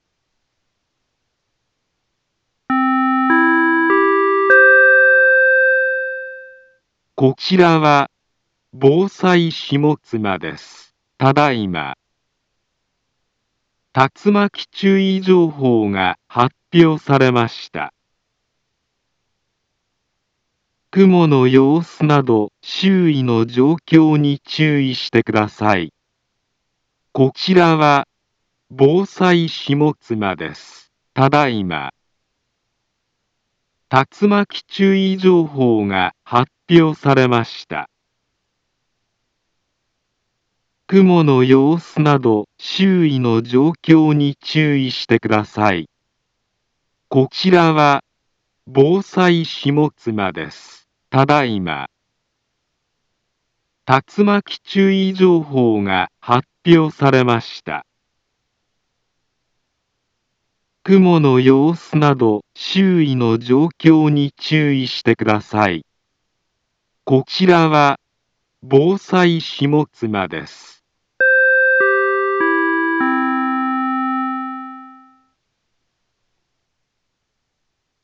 Back Home Ｊアラート情報 音声放送 再生 災害情報 カテゴリ：J-ALERT 登録日時：2025-08-08 17:38:28 インフォメーション：茨城県北部、南部は、竜巻などの激しい突風が発生しやすい気象状況になっています。